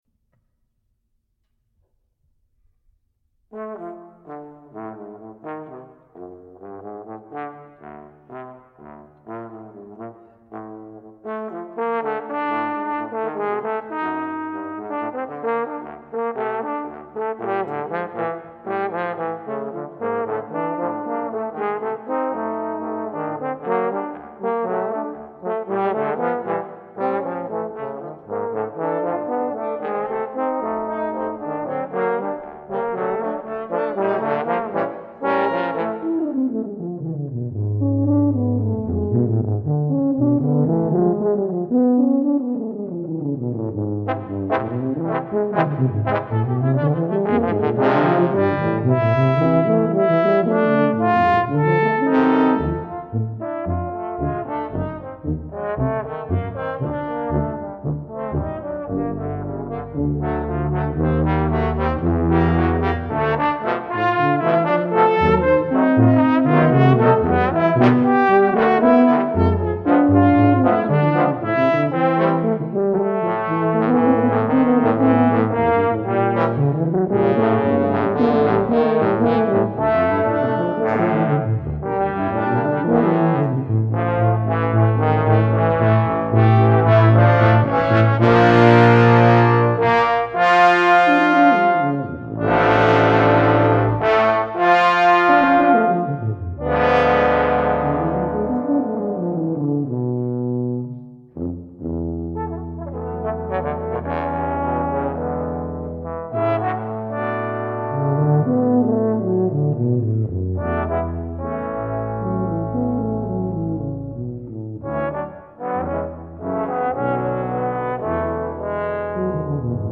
For Brass Ensemble
Arranged by . 6 Trombones and 2 Tubas.